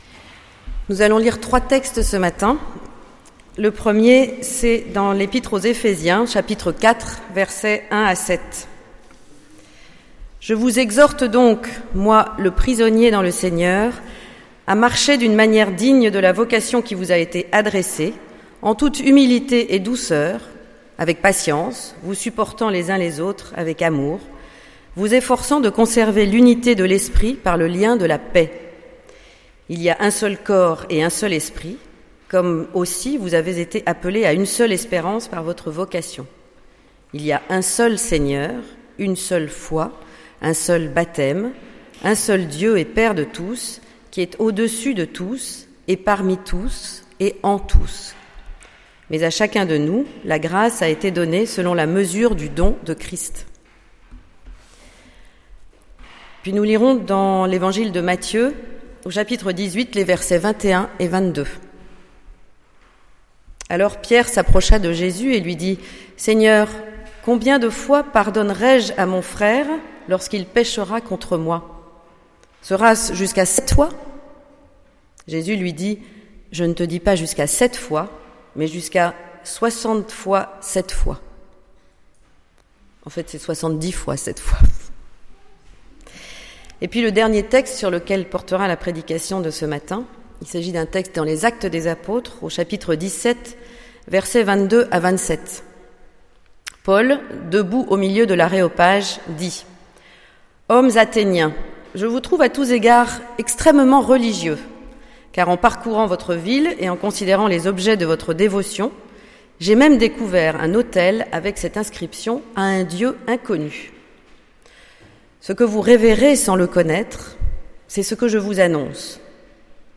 Prédication du 7 juin 2015